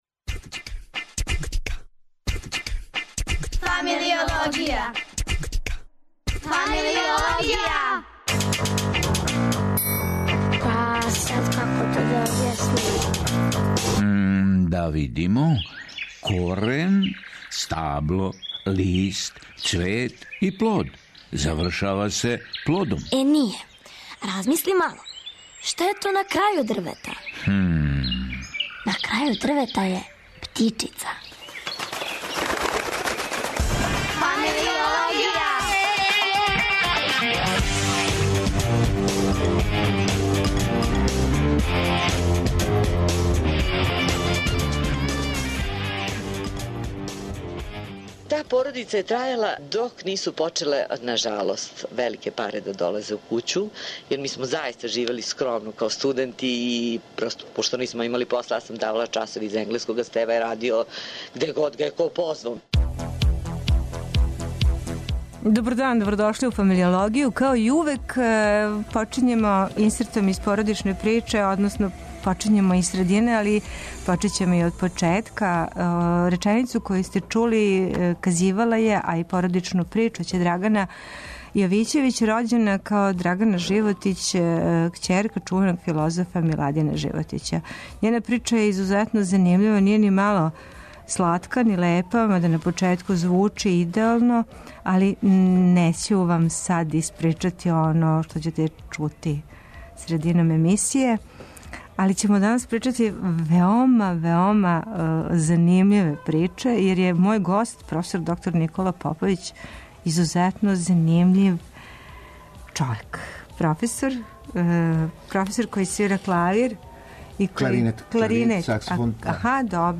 Радио Београд 1, 13.05